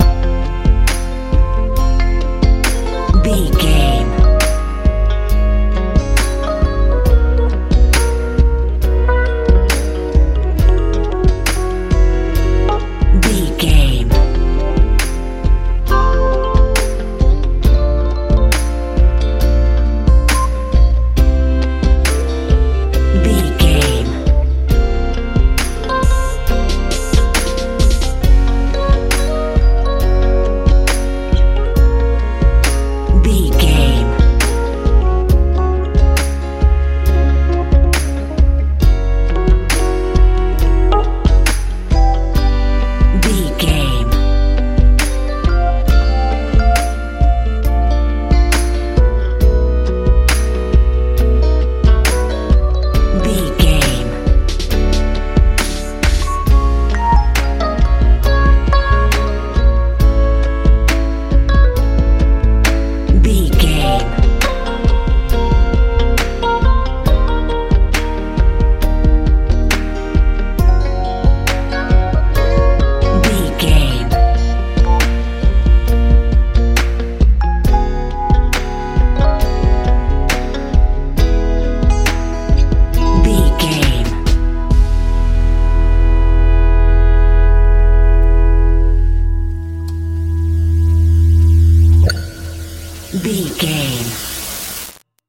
modern rnb feel
Music
Ionian/Major
light
soothing
horns
organ
bass guitar
drums
soft
relaxed
mellow